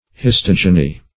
Search Result for " histogeny" : The Collaborative International Dictionary of English v.0.48: Histogeny \His*tog"e*ny\, n. [Gr.